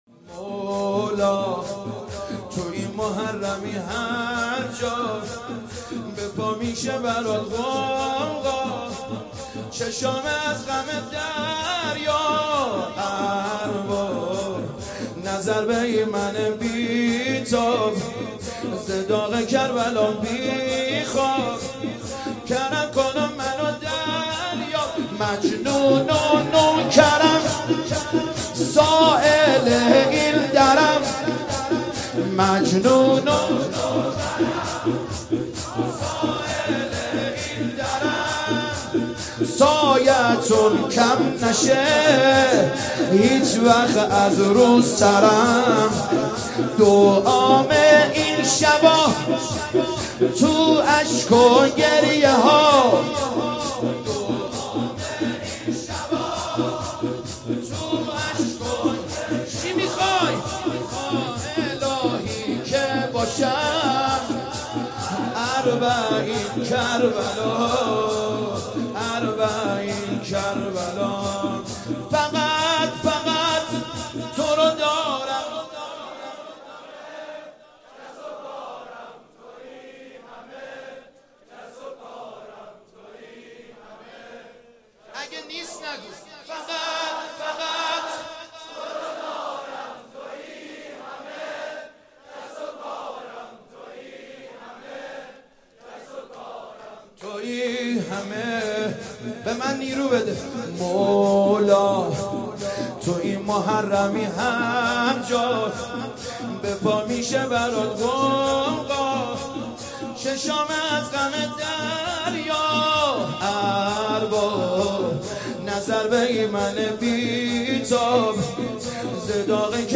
هیات کربلا رفسنجان
شور